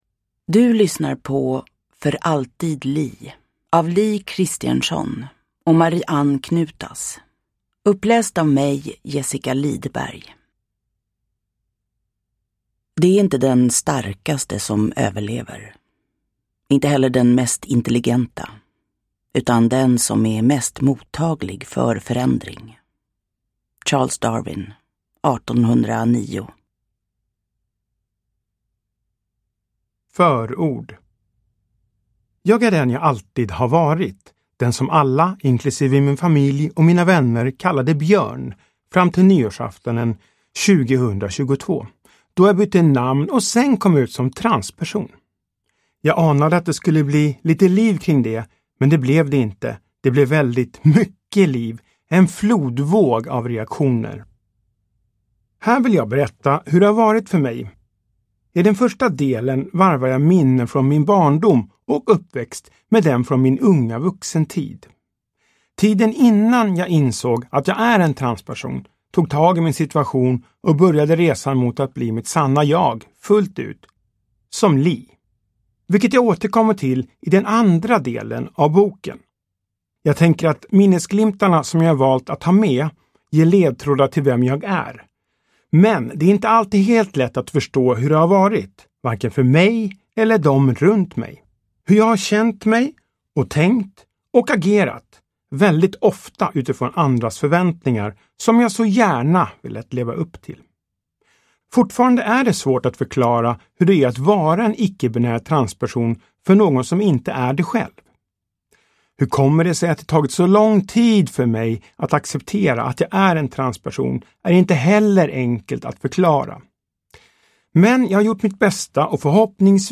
För alltid Lee – Ljudbok
Uppläsare: Jessica Liedberg